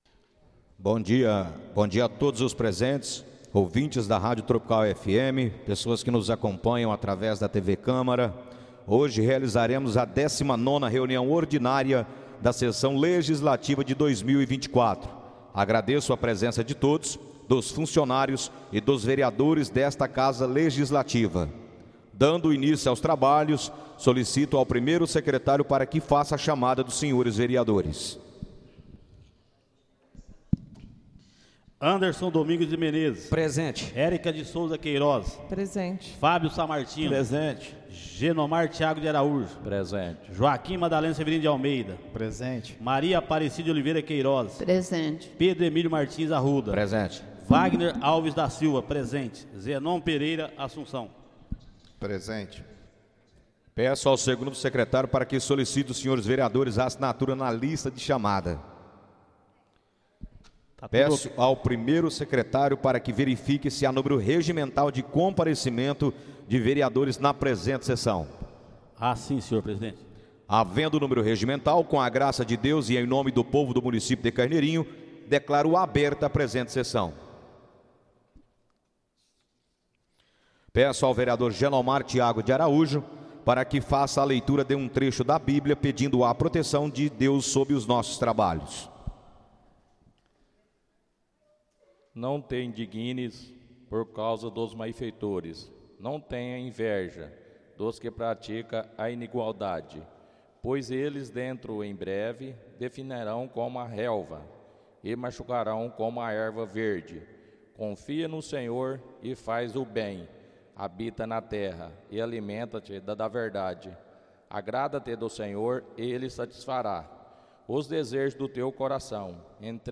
Audio da 19.ª reunião ordinária de 2024, realizada no dia 26 de Novembro de 2024, na sala de sessões da Câmara Municipal de Carneirinho, Estado de Minas Gerais.